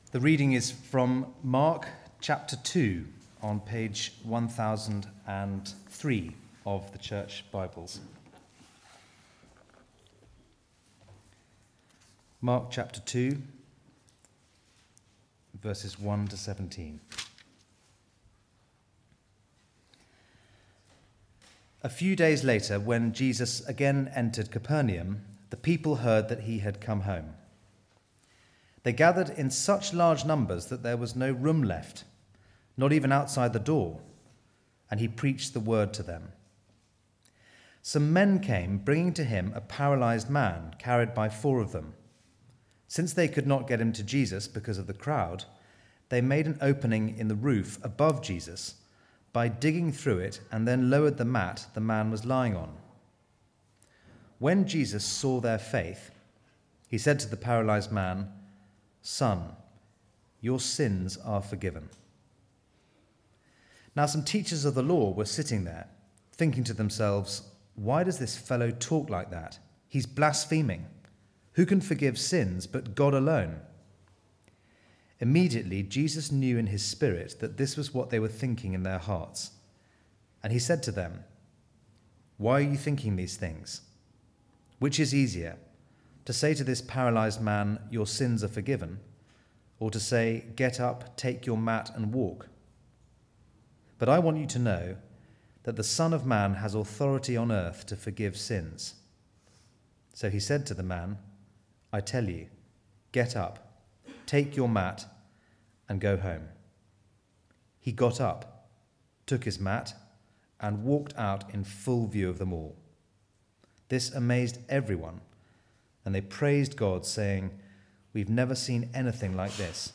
Follow the King Passage: Mark 2:1-17 Service Type: Weekly Service at 4pm « “What is this?